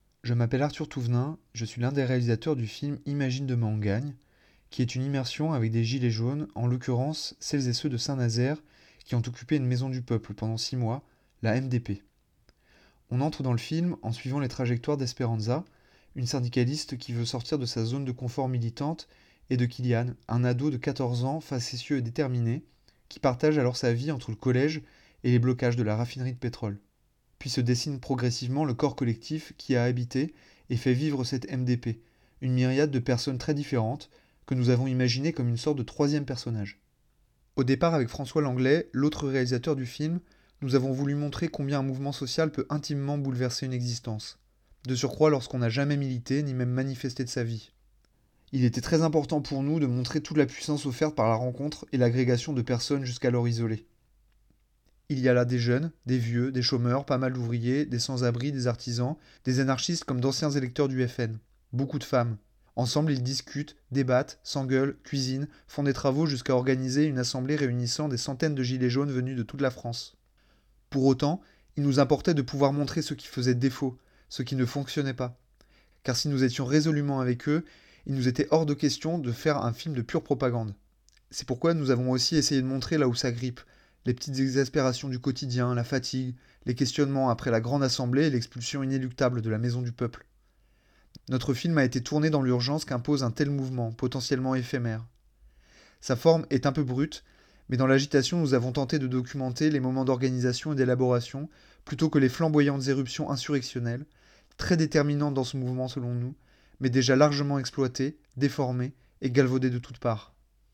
Ecoutez les réalisateur·ices vous parler de leurs films